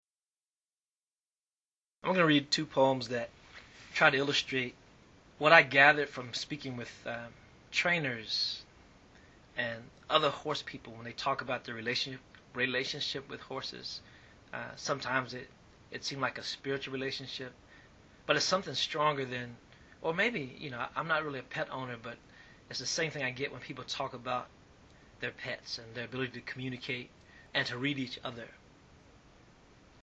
Frank X Walker introduces his poems “Groom and “Horseshoes Only Lucky When They Miss.”